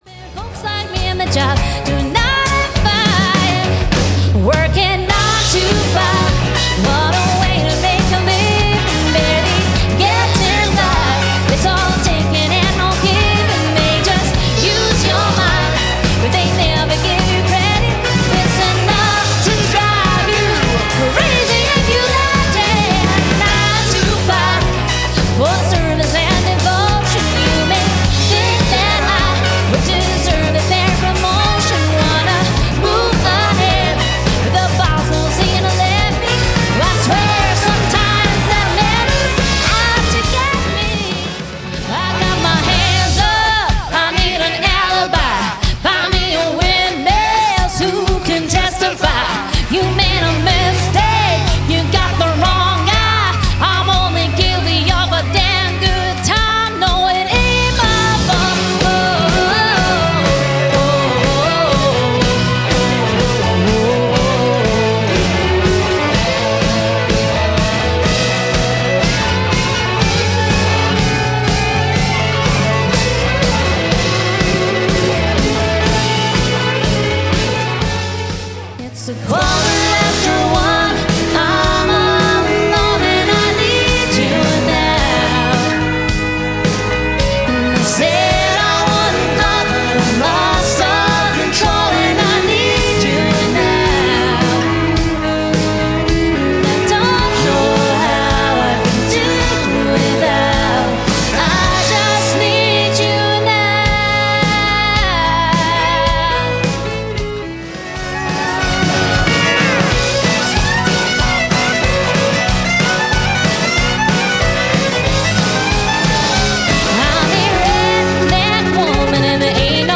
Upbeat and modern country band based in Scotland.
• Upbeat, fun and lively modern country band
• Incredible 3 part harmonies in every lineup!
• Featuring fiddle and banjo for a traditional country sound
• Authentic country-sounding lead vocals